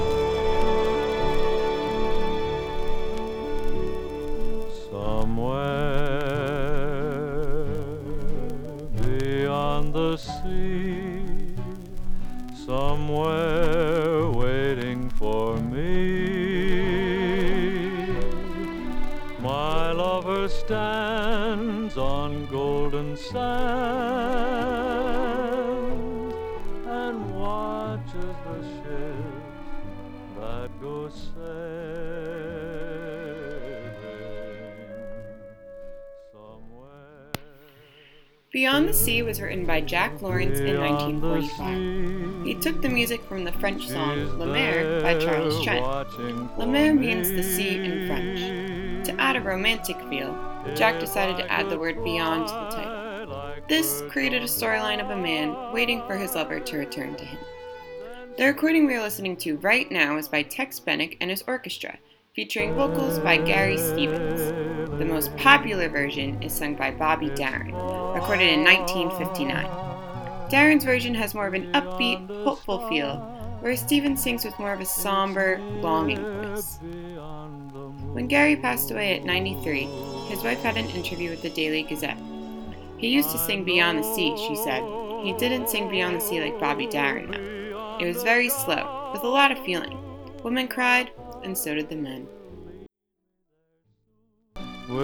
vocals
somber, longing voice